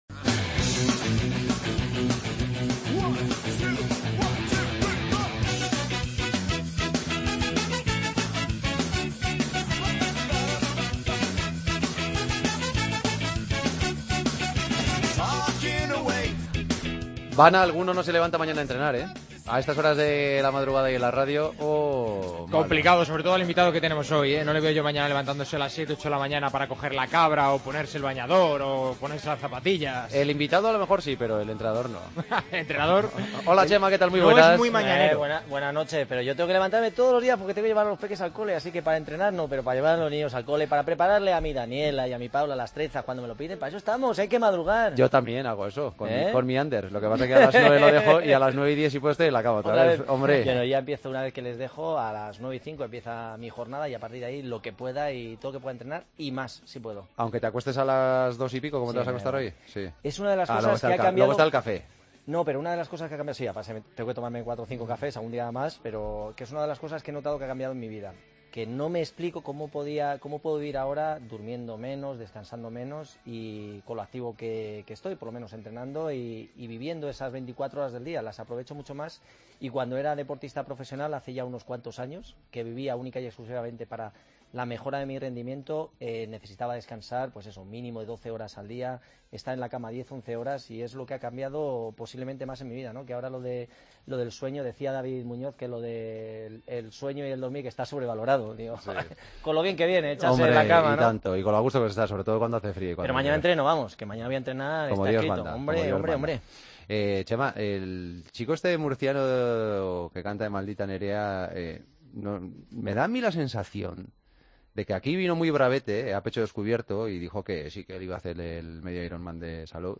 AUDIO: Chema Martínez se vino a nuestros estudios junto a otro gran deportista: el cantante de Maldita Nerea, Jorge Ruiz.